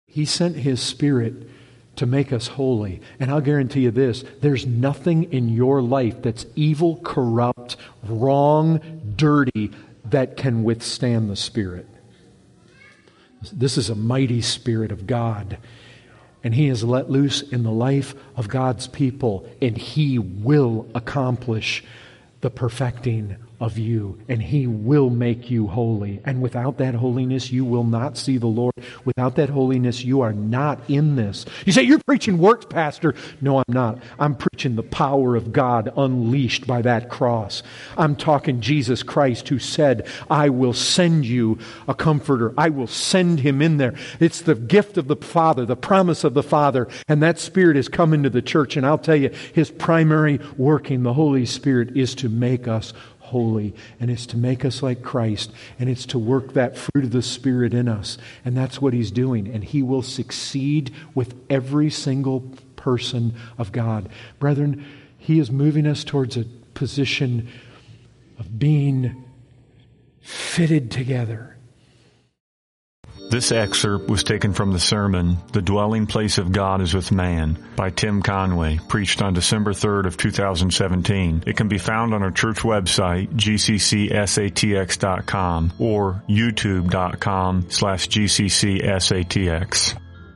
1:31 | Excerpt | There is nothing in your life that is evil, corrupt, wrong, dirty, that can withstand the Spirit.